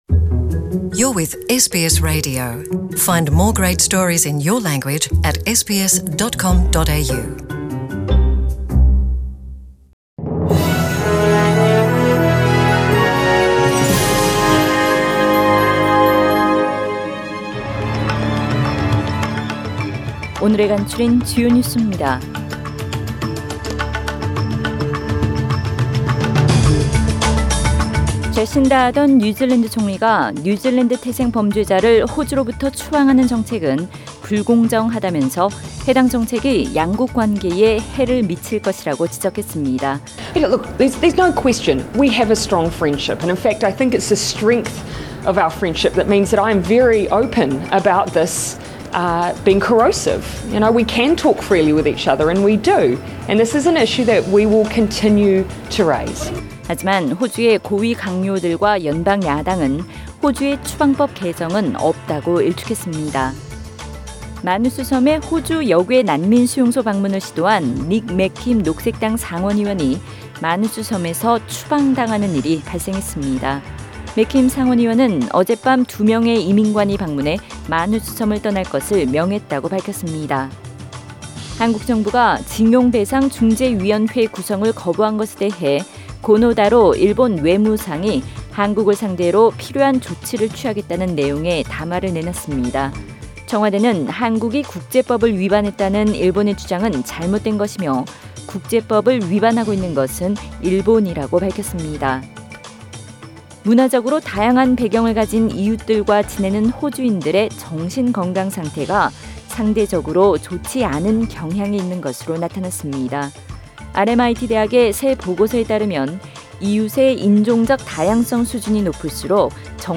2019년 7월 19일 금요일 저녁의 SBS Radio 한국어 뉴스 간추린 주요 소식을 팟 캐스트를 통해 접하시기 바랍니다.